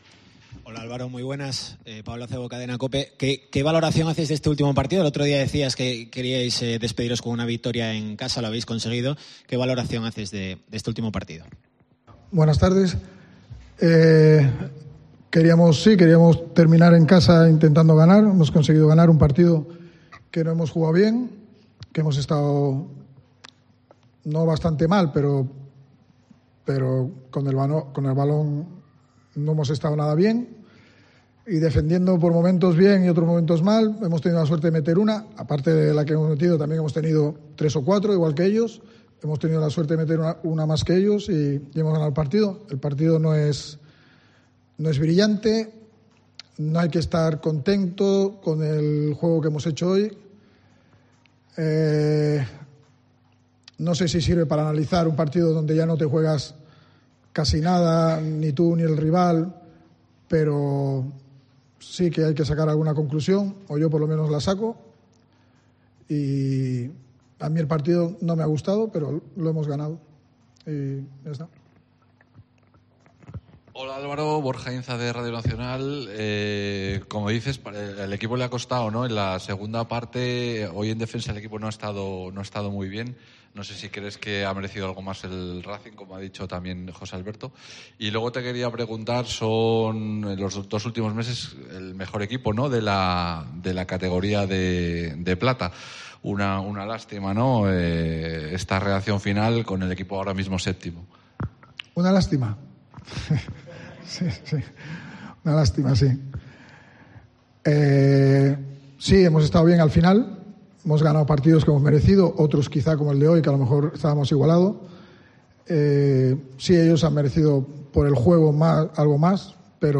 La rueda de prensa de Álvaro Cervera (post Racing)